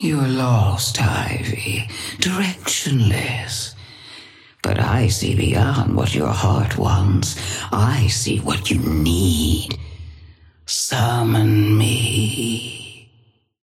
Sapphire Flame voice line - You're lost, Ivy. Directionless. But I see beyond what your heart wants. I see what you need. Summon me.
Patron_female_ally_tengu_start_01_alt_01.mp3